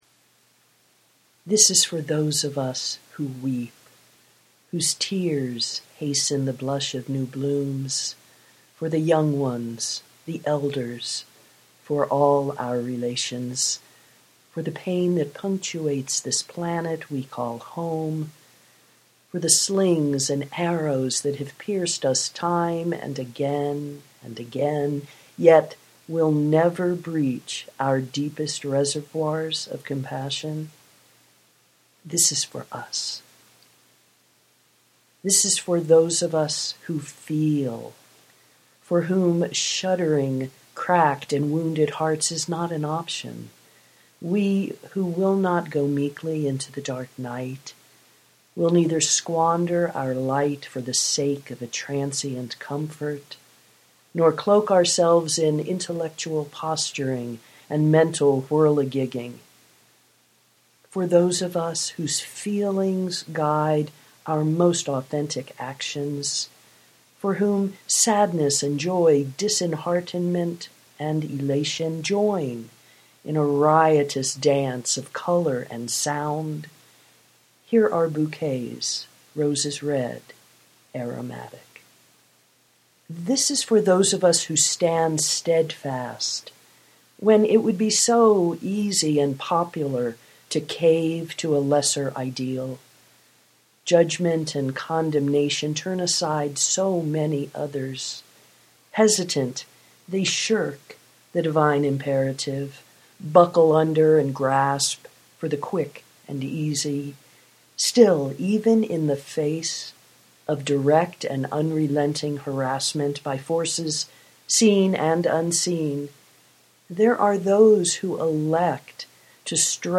for those of us who weep (audio poetry 3:32)